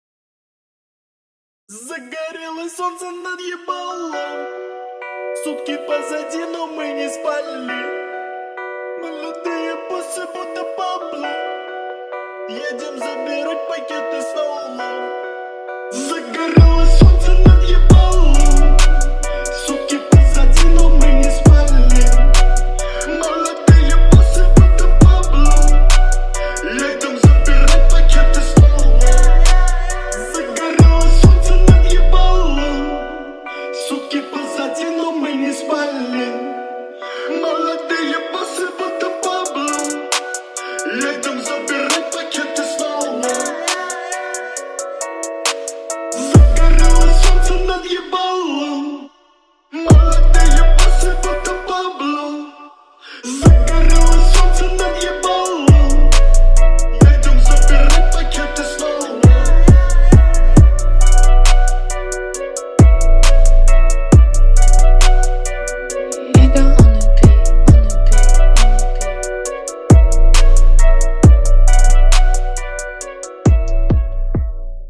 выделяется мощным битом и запоминающейся мелодией